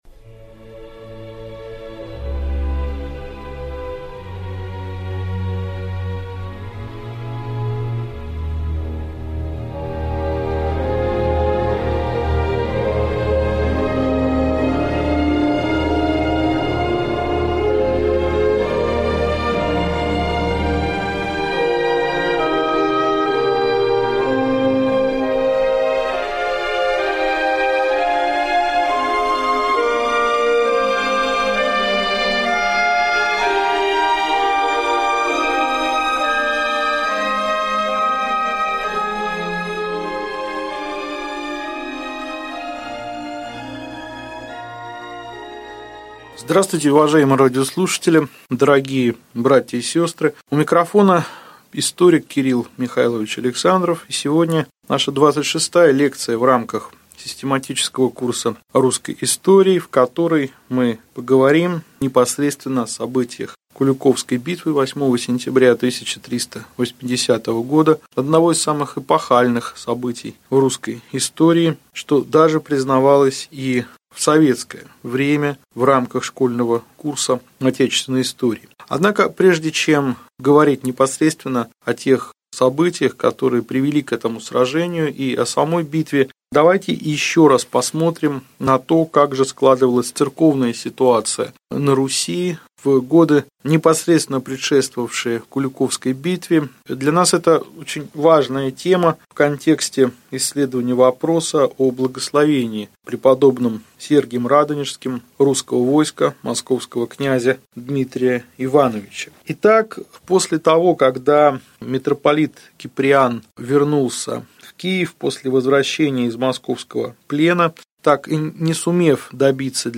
Аудиокнига Лекция 25. Отношения кн. Дмитрия Донского с прп. Сергием Радонежским | Библиотека аудиокниг